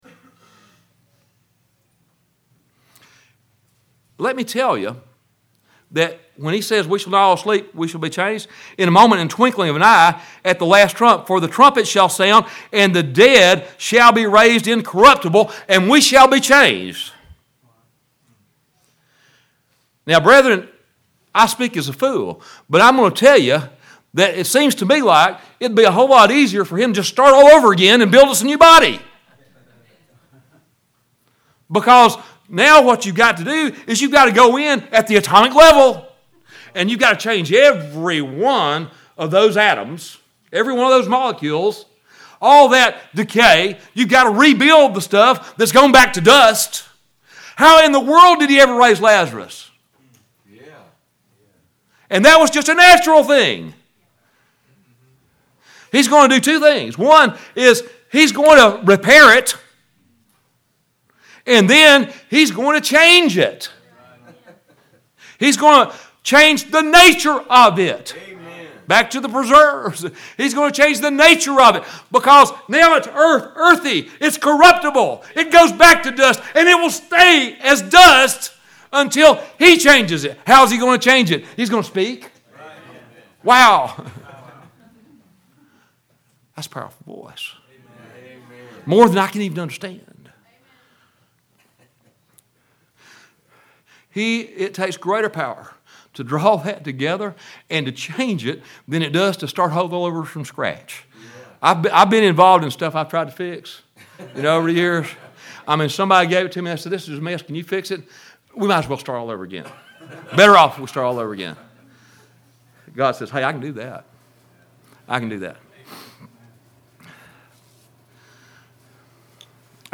02/09/14 Sunday Morning Florida Fellowship Meeting - Macclenny Primitive Baptist Church